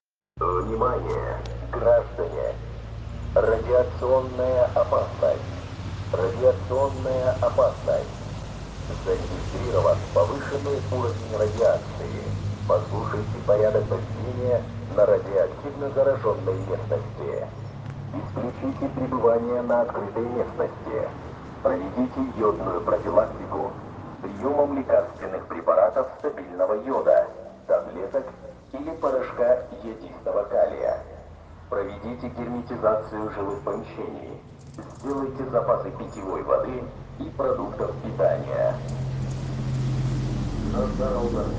А вот в Есике жители услышали чуть ли не объявление войны!
Сирена 1
По громкоговорителю советовали занять ближайшее защитное сооружение и ждать там отбоя воздушной тревоги.